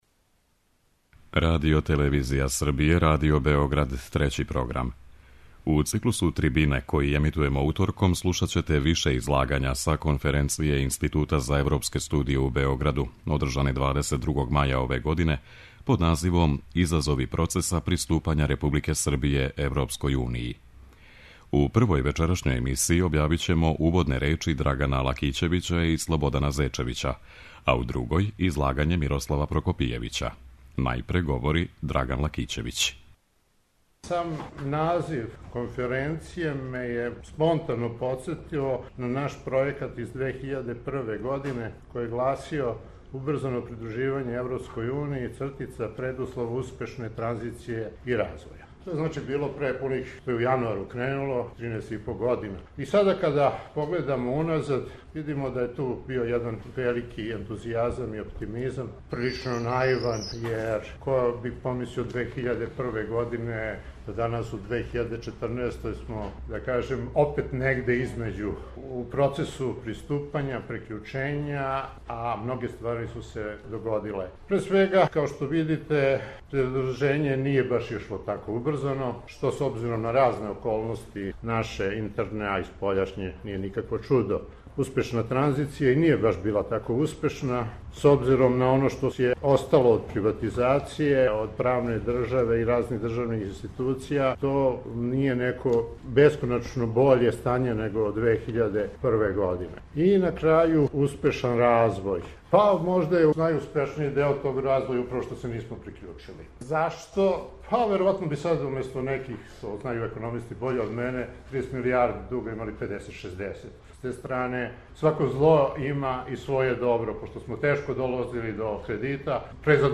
Трибине